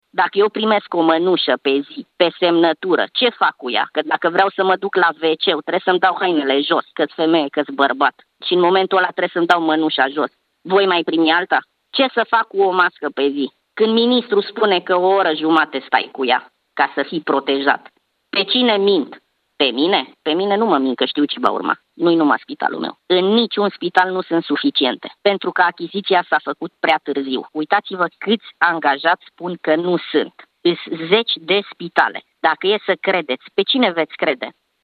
Un doctor de la un spital din Timișoara povestește la Europa FM că achizițiile s-au făcut insuficient și tardiv.
29mar-11-medic-primesc-o-masca-pe-zi-DISTORSIONAT.mp3